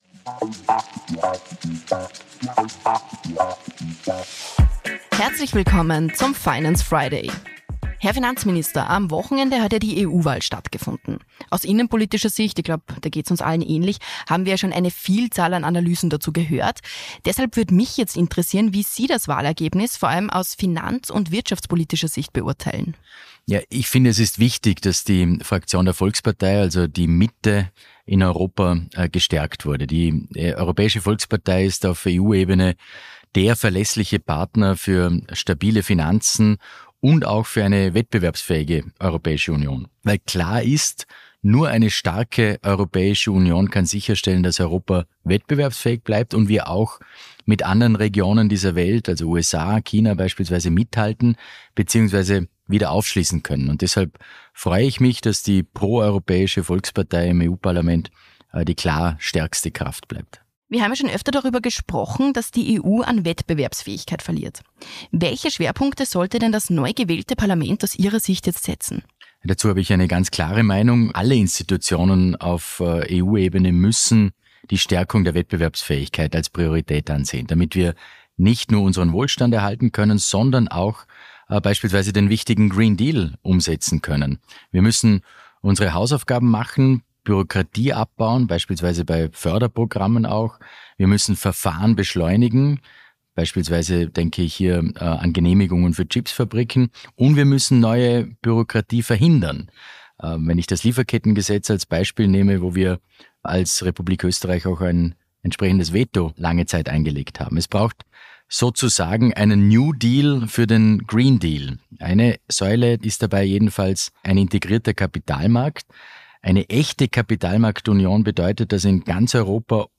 In der aktuellen Folge des „Finance Friday“ spricht Finanzminister